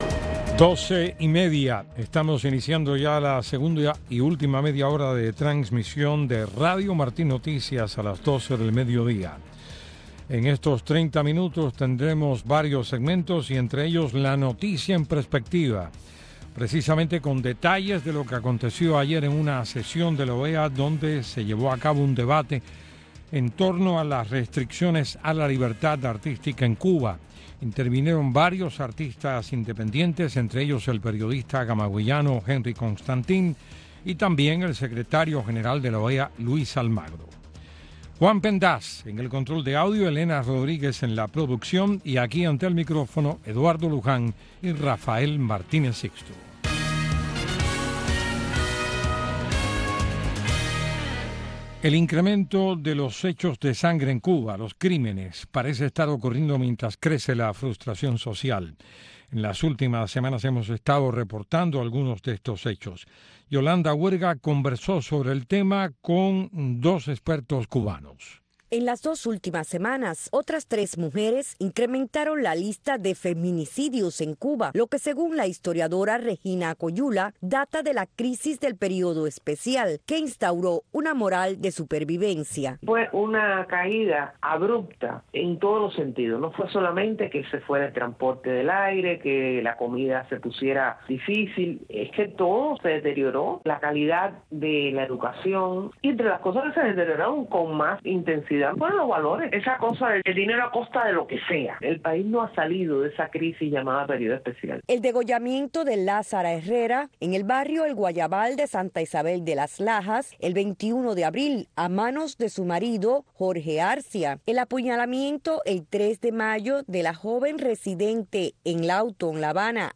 Más de 50 entrevistas, testimonios, recuentos y anécdotas de laicos, religiosas y sacerdotes que repasan la historia de la Arquidiócesis de Miami y la huella de los exiliados cubanos en su crecimiento.